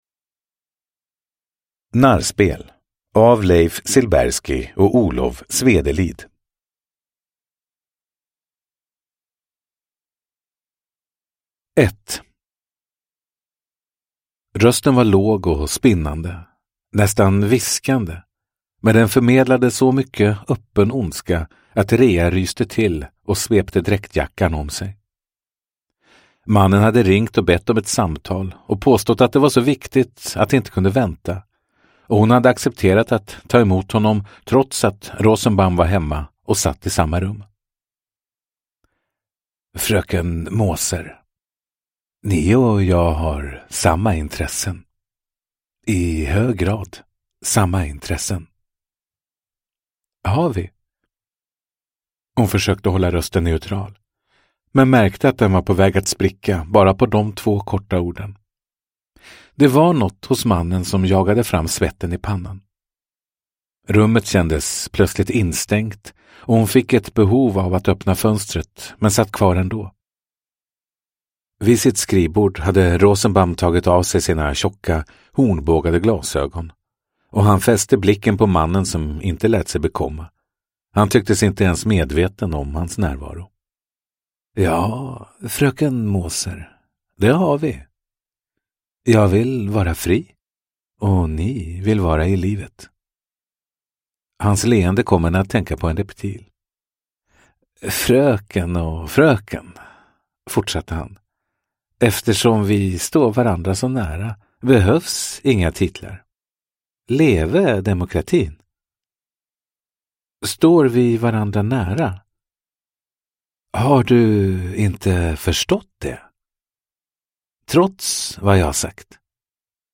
Narrspel – Ljudbok – Laddas ner